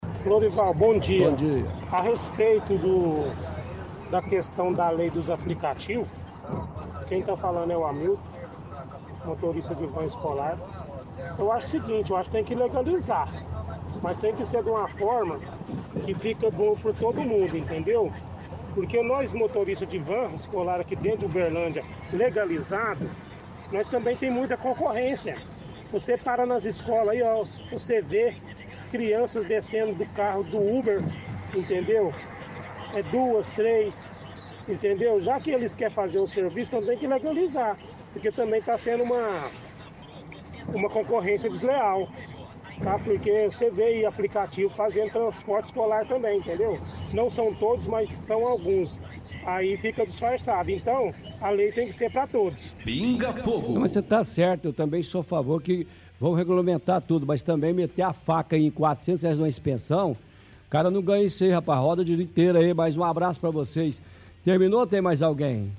– Ouvinte, motorista de vans, defende que os aplicativos tem que ser legalizados, mas de forma que fique bom para todos, citando que perde clientes para os motoristas de aplicativo.